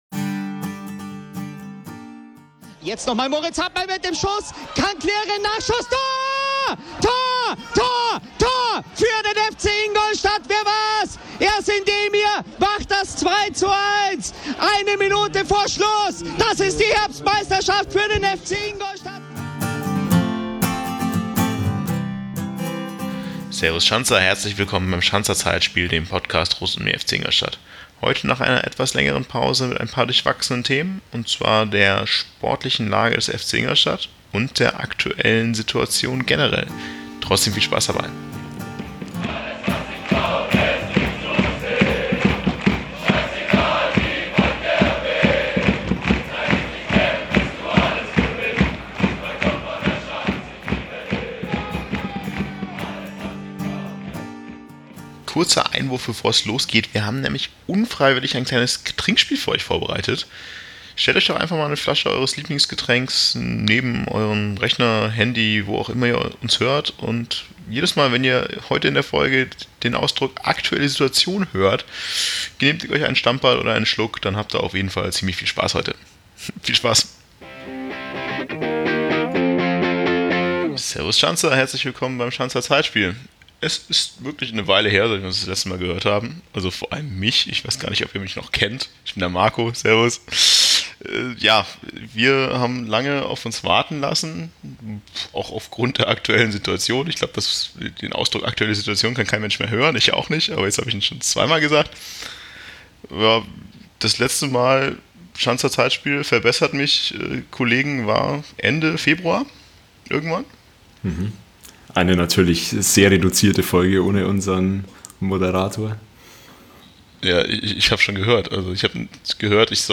Das Corona-Virus hat die Gesellschaft und die Fußballwelt fest im Griff und jetzt meldet sich auch noch das Zeitspiel-Trio zu Wort - man ahnt nichts gutes. Gerade weil seit der letzten Aufnahme schon einige Zeit verstrichen ist, gibt es einiges zu bereden: die Spiele gegen Rostock, Köln und Halle, einen Trainerwechsel und natürlich die "aktuelle Situation" mit möglichen Szenarien wie es nach der fußballfreien Zeit weitergehen könnte.